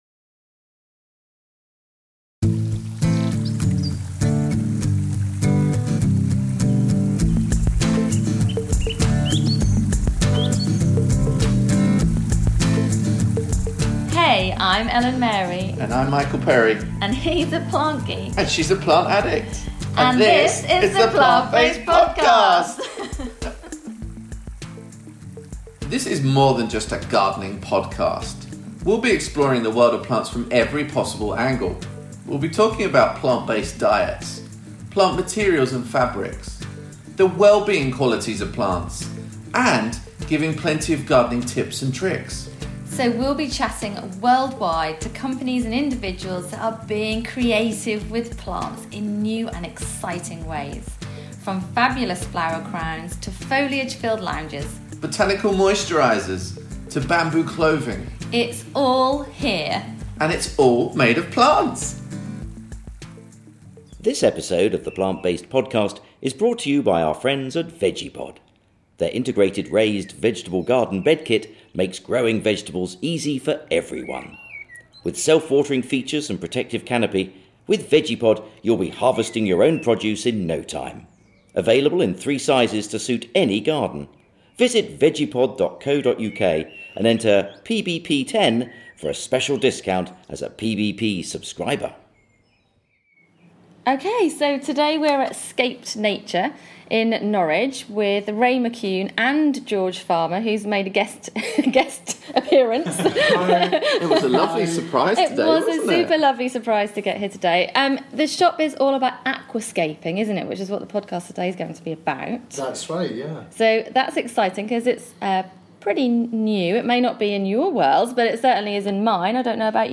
Interested in trying out aquascaping for the first time? We visited Scaped Nature to find out all about this trendy art.